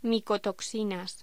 Locución: Micotoxinas
voz